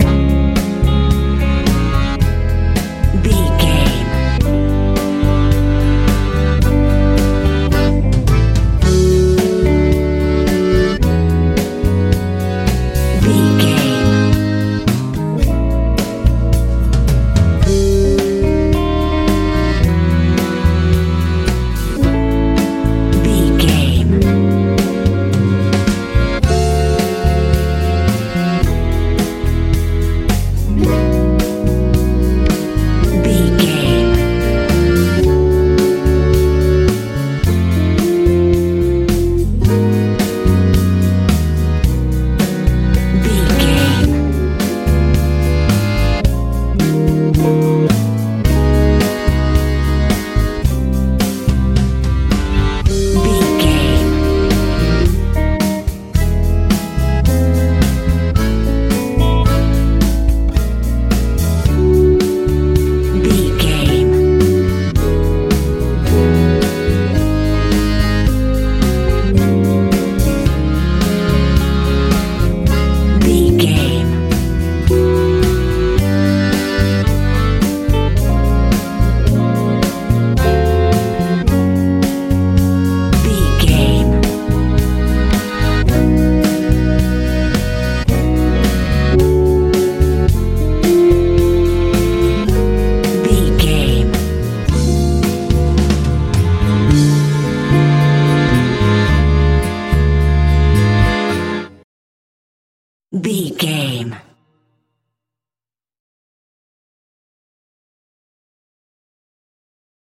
pop ballad feel
Ionian/Major
C♯
calm
light
accordion
bass guitar
electric guitar
acoustic guitar
drums
driving